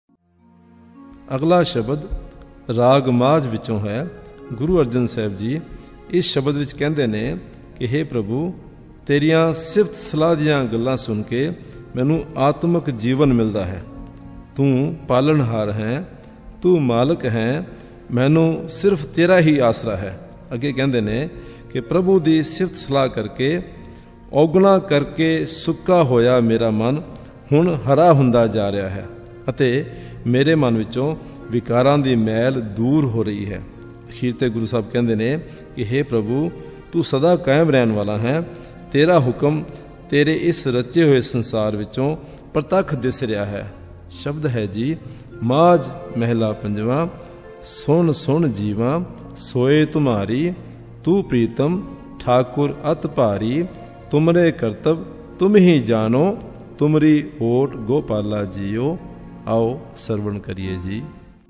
Sung in Raag Maajh : Taals: Jhap Taal (10), Teen Taal (16).